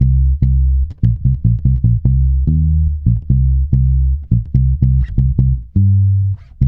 -MM RAGGA C.wav